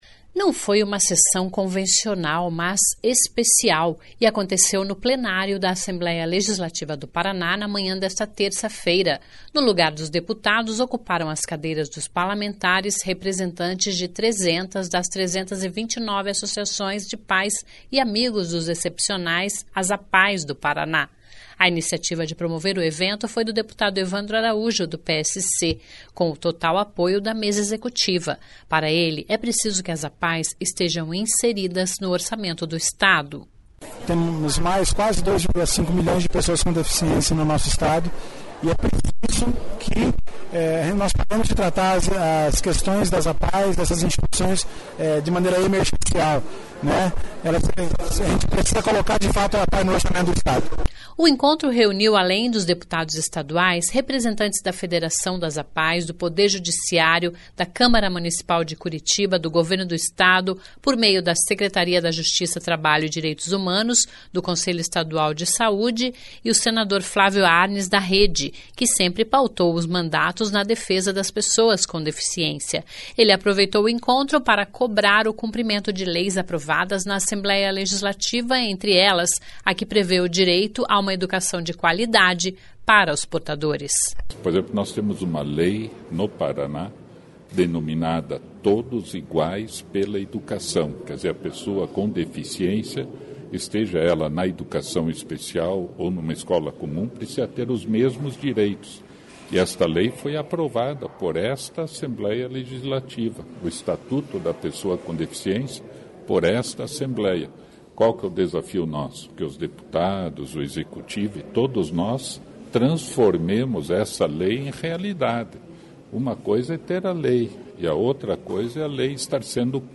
Não foi uma sessão convencional, mas especial; e aconteceu no Plenário da Assembleia Legislativa do Paraná (Alep), na manhã desta terça-feira (30). No lugar dos deputados, ocuparam as cadeiras dos parlamentares representantes de 300 das 329 Associações de Pais e Amigos dos Excepcionais (APAES) do Paraná.